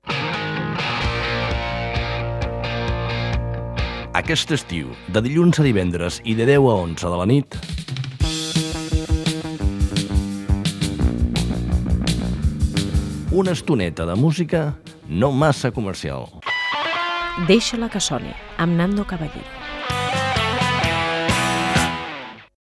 Promoció del programa
Musical